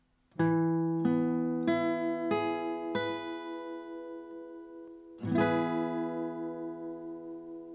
E-Dur (Barré, A-Saite)
Hier ist es besonders wichtig, die E-Saite mit der Spitze des Zeigefingers abzudämpfen.
E-Dur-Barre-A.mp3